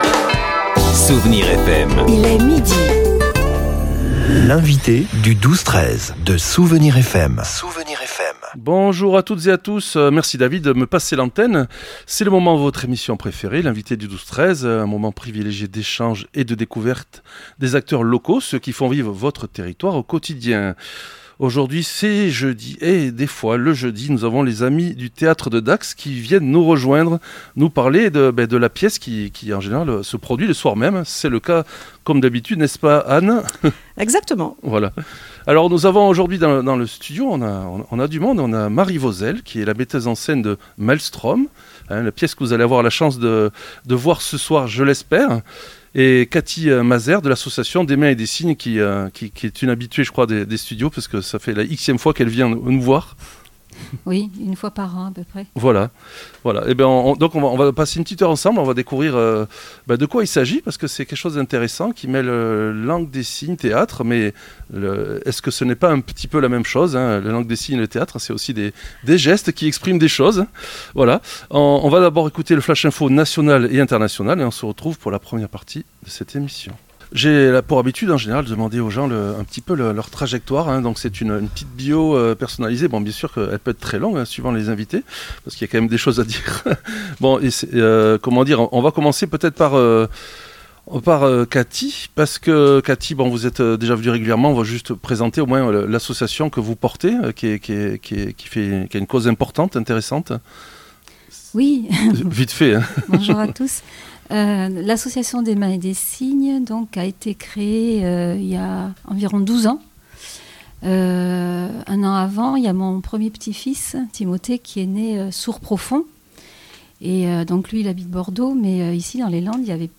Nous avions la chance d'avoir trois femmes dans le studio, toutes impliquées dans le spectacle visible dès cet après-midi et ce soir à L'ATRIUM de Dax.